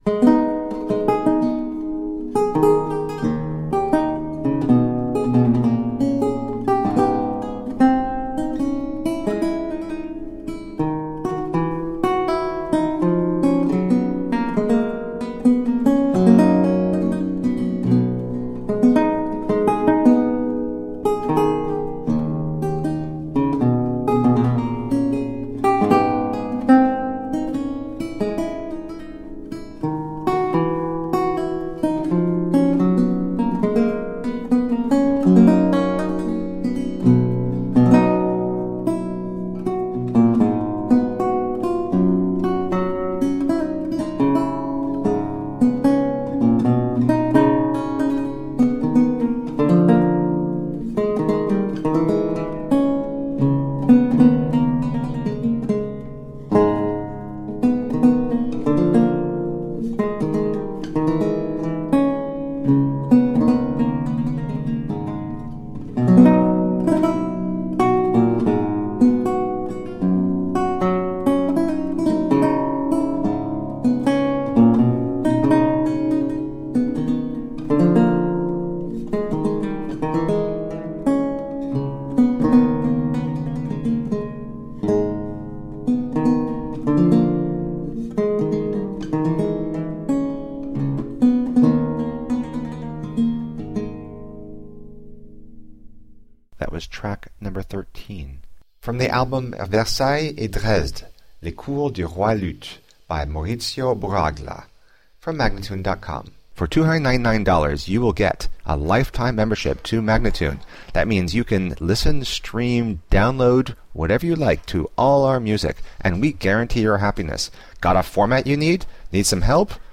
A marvelous classical spiral of lute sounds.